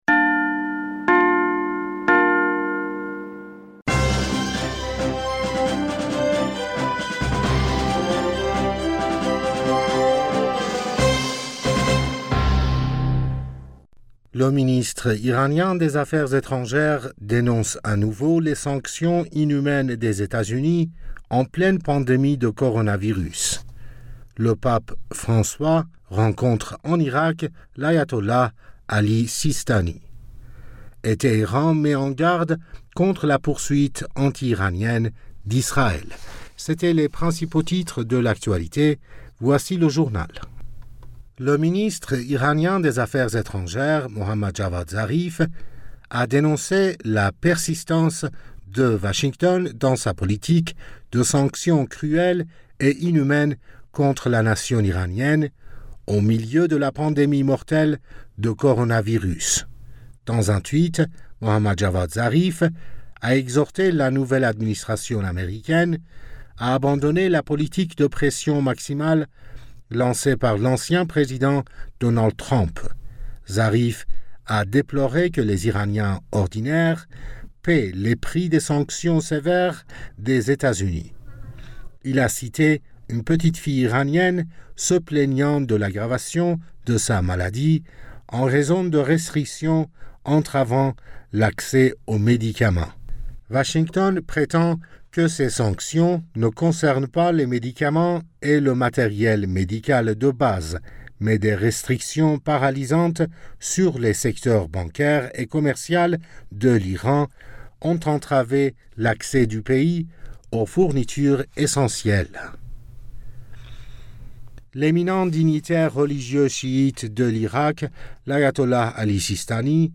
Bulletin d'informationd u 06 Mars 2021